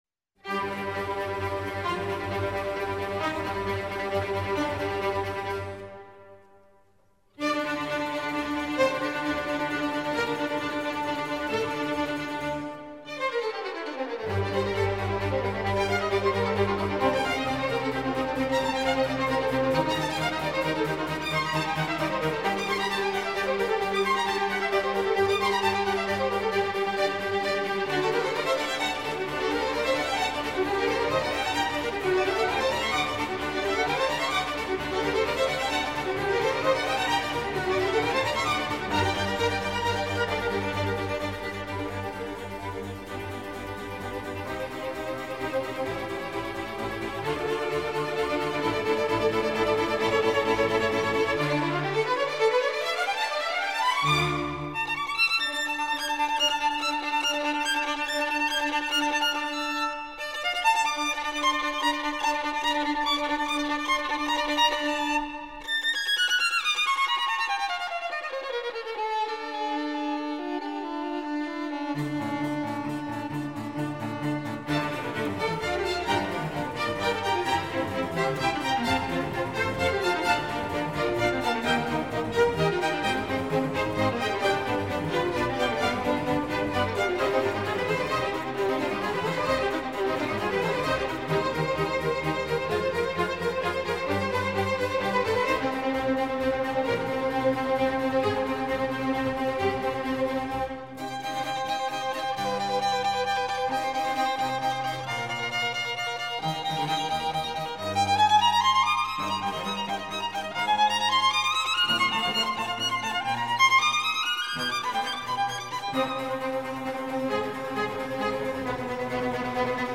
موسیقی بی‌کلام "چهرفصل" بخش "تابستان" موومان سوم، آهنگساز: آنتونیو ویوالدی